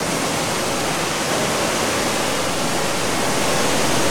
3TD_Cave_Waterfall.wav